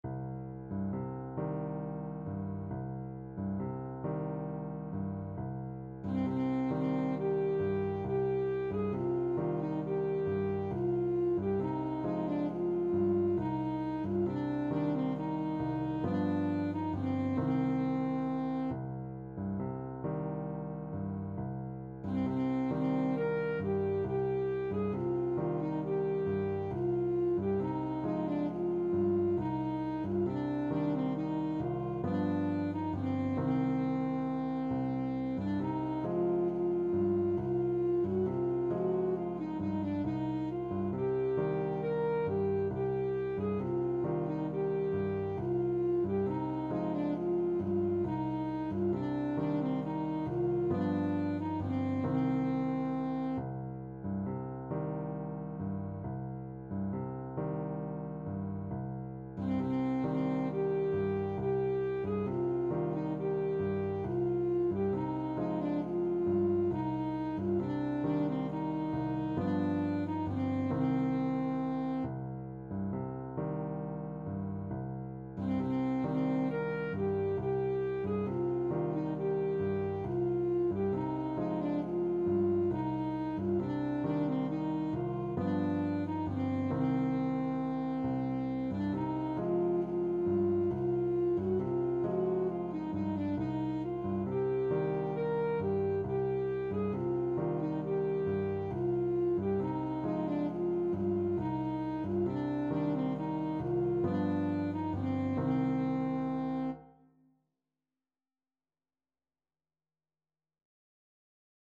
Alto Saxophone
6/8 (View more 6/8 Music)
C minor (Sounding Pitch) A minor (Alto Saxophone in Eb) (View more C minor Music for Saxophone )
Gently rocking .=c.45
Turkish